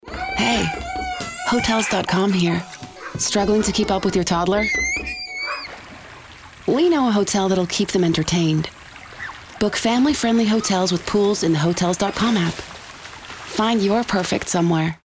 Female
Approachable, Assured, Confident, Conversational, Corporate, Energetic, Engaging, Natural
Microphone: Rode Nt1-A